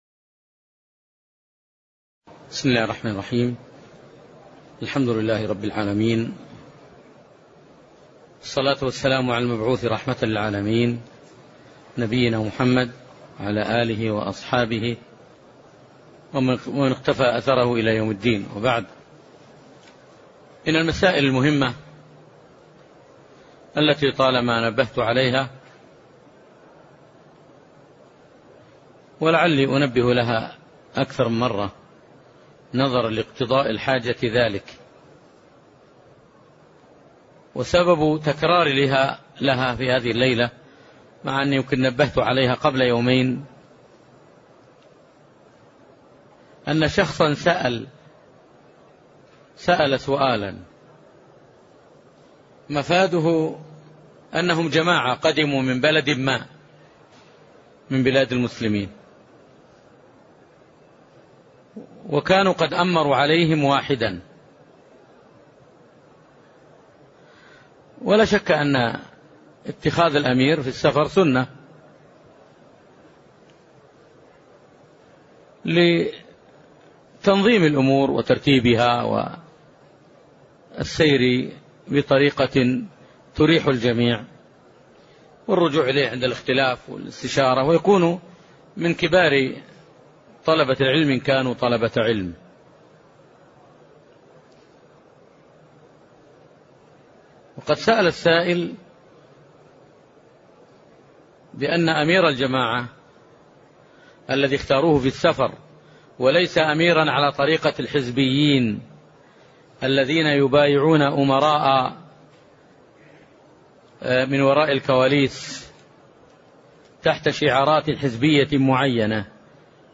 تاريخ النشر ٧ رمضان ١٤٢٨ هـ المكان: المسجد النبوي الشيخ